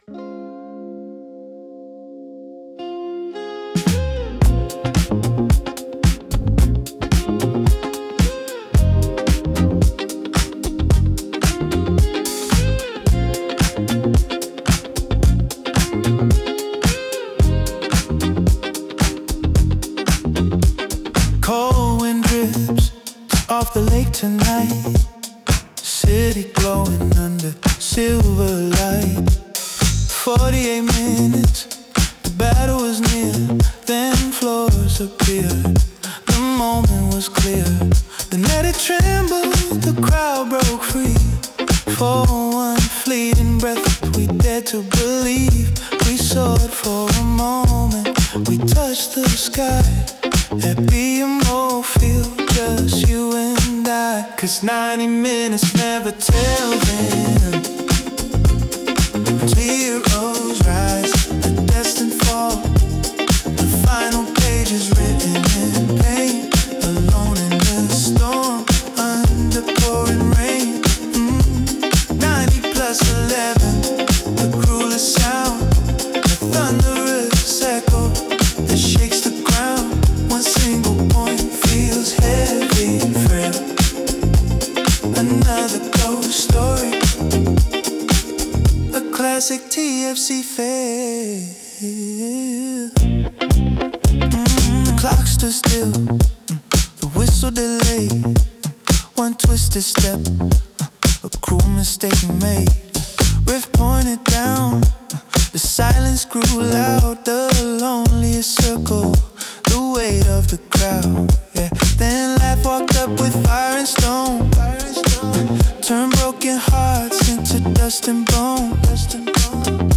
Una canción mordaz sobre la obsesión por las estadísticas. Aquí la burla es fina y el groove te invita a mover la cabeza mientras miras la pizarra con incredulidad.